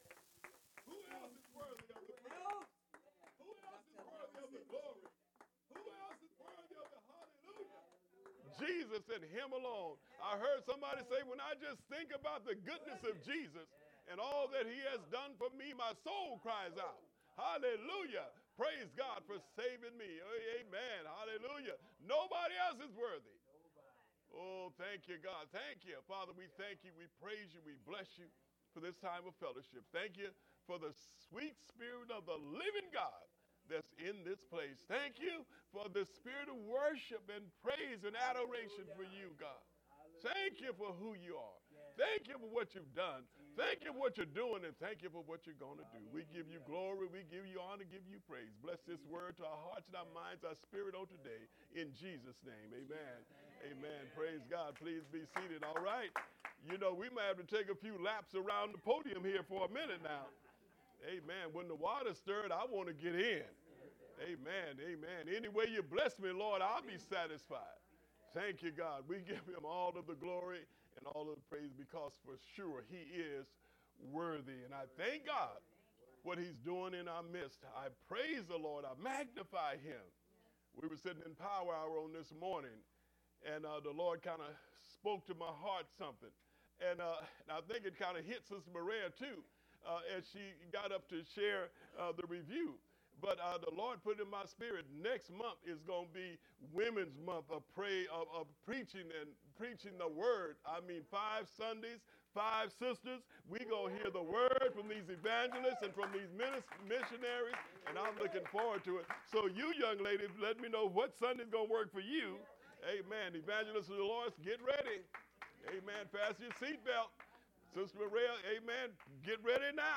Sermons by Prayer, Praise & Proclamation Christian Ministries (3Ps)